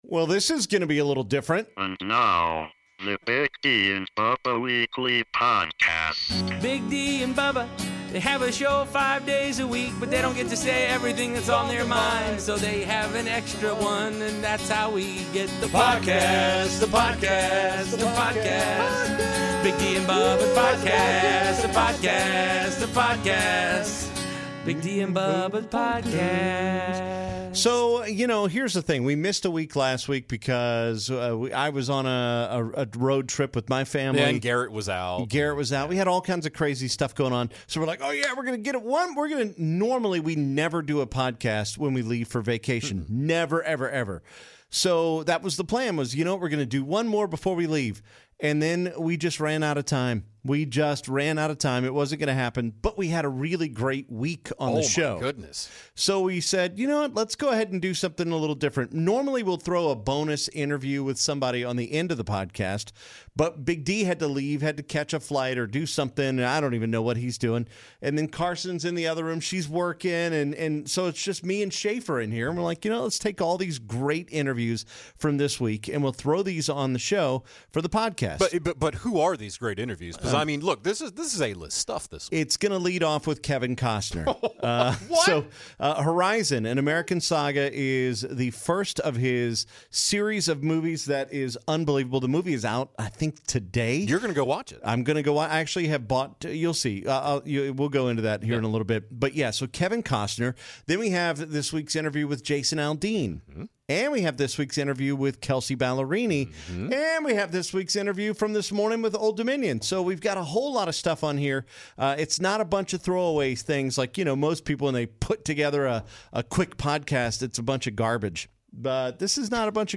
This past week, we sat down with Kevin Costner, Jason Aldean, Kelsea Ballerini, and Old Dominion!